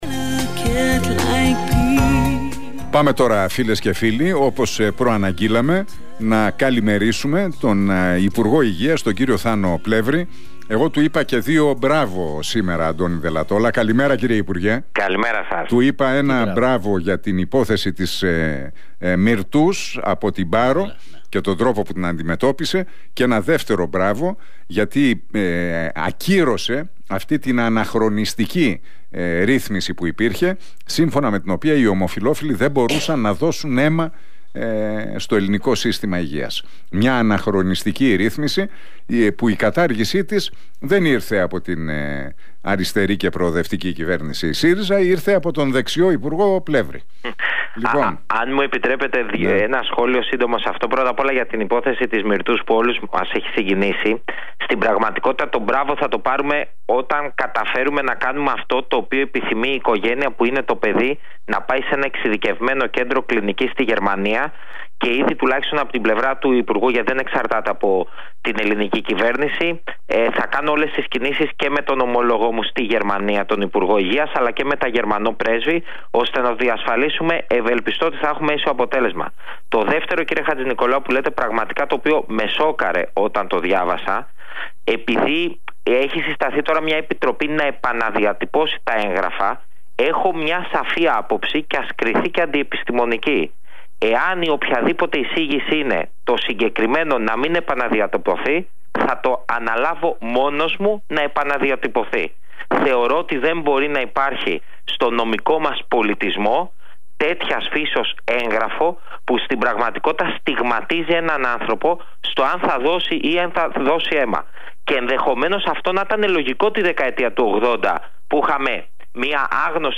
Ο υπουργός Υγείας, Θάνος Πλεύρης, σε συνέντευξή του στον Realfm 97,8